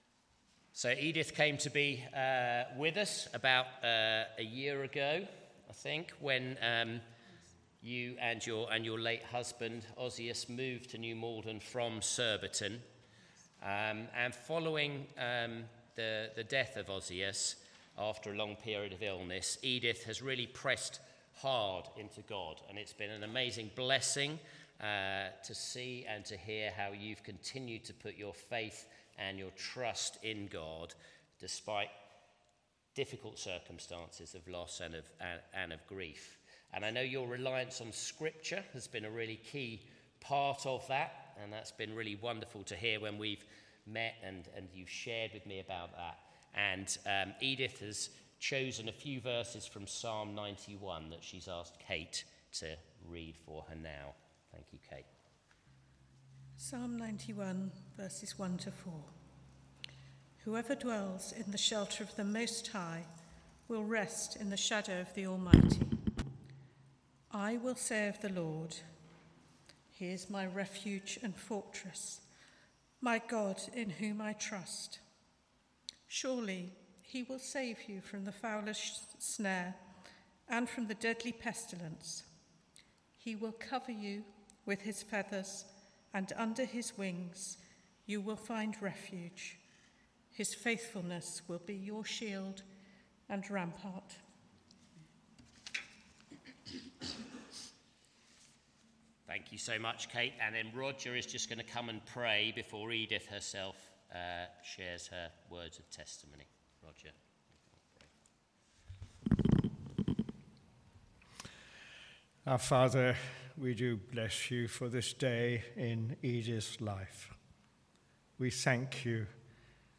Sunday Service
Sermon